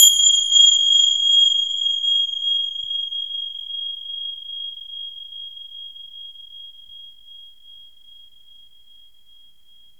BELL#1.wav